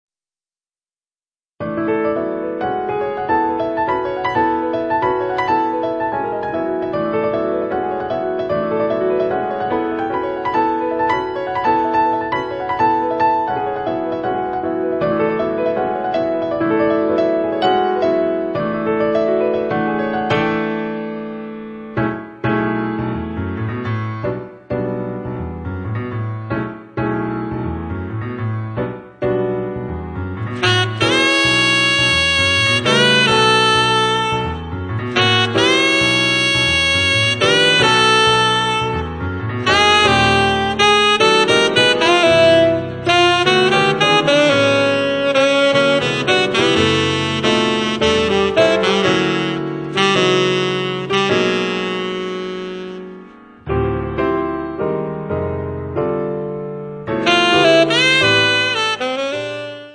pianoforte, fender rhodes
sax tenore e soprano